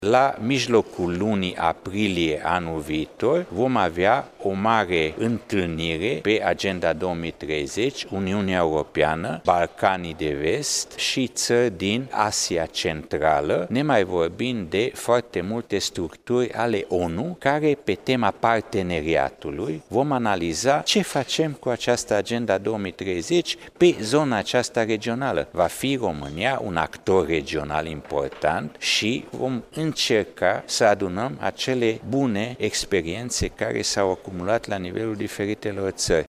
Laszlo Borbely a mai spus că, până în vară, strategia de dezvoltare durabilă va fi definitivată urmând ca decidenţii politici să stabilească modalităţile de aplicare a acesteia.